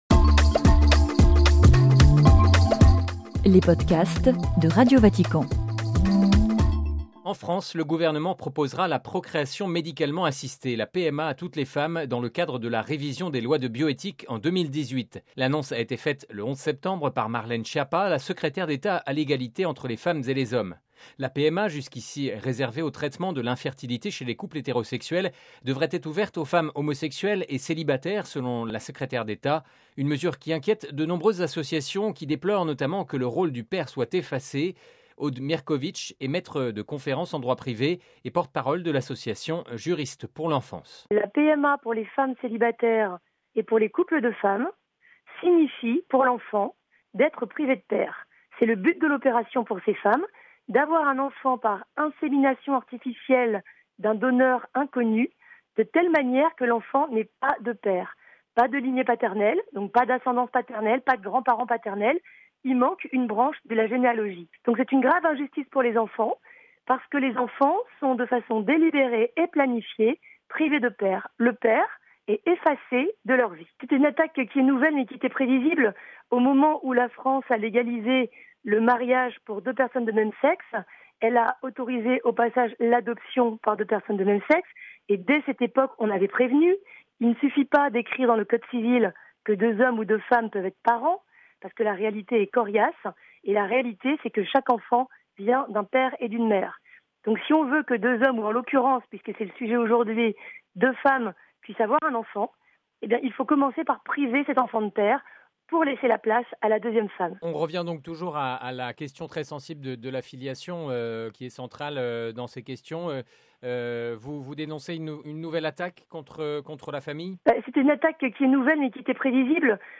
(RV) Entretien - En France, le gouvernement d'Edouard Philippe proposera la Procréation Médicalement Assistée (PMA) à toutes les femmes, dans le cadre de la révision des lois de bioéthique en 2018.